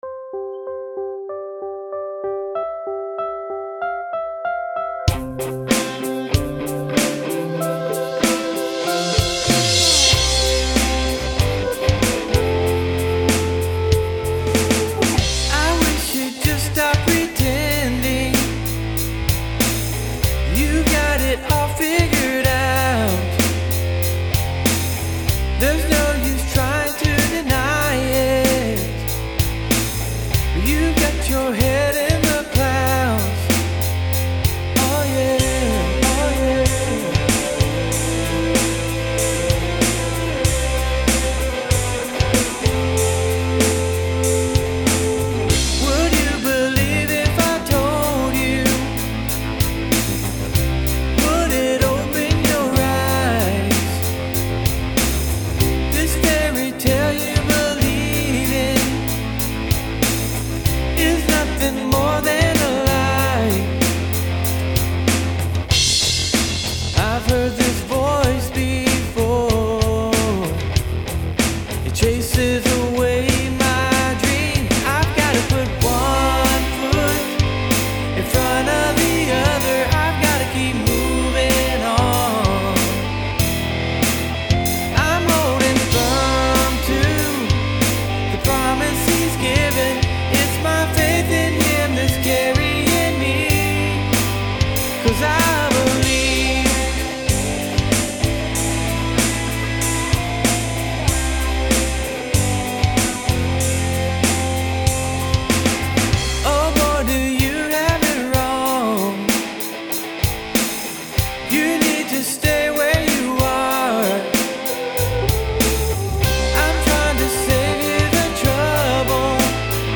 Genre: CCM